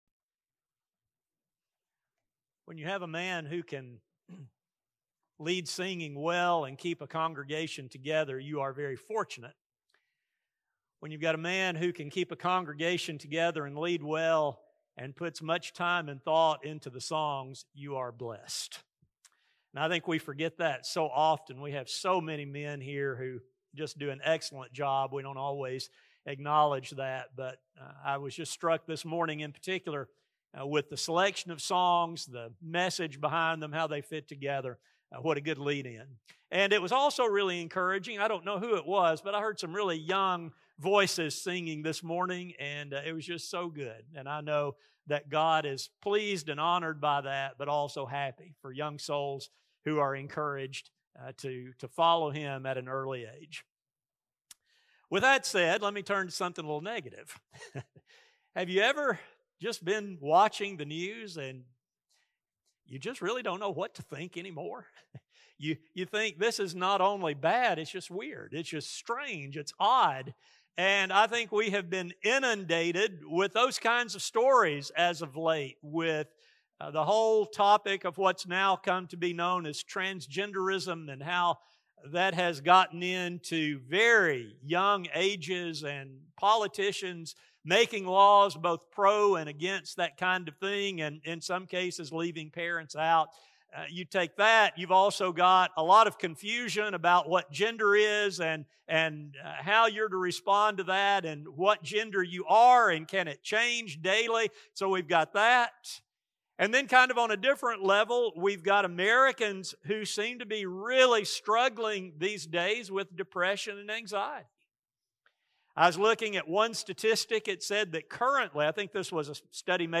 Finding this attitude requires an understanding of what true contentment means and knowing who can provide it. A sermon